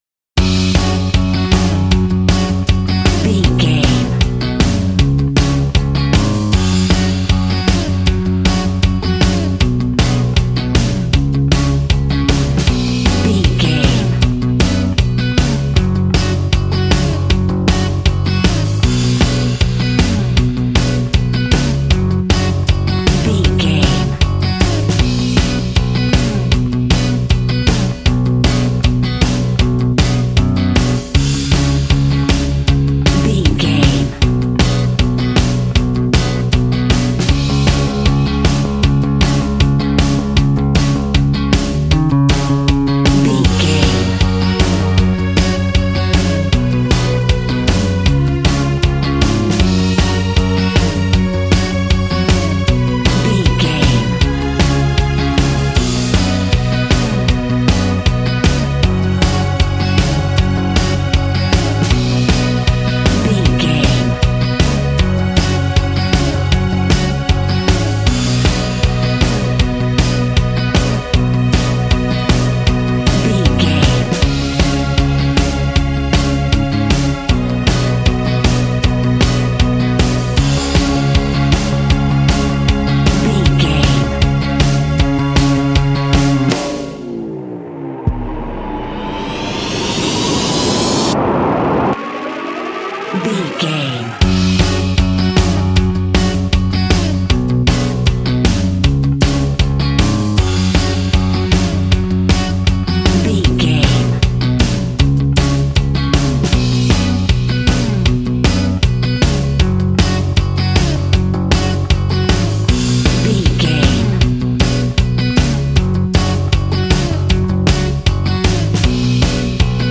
Ionian/Major
G♭
groovy
powerful
organ
drums
bass guitar
electric guitar
piano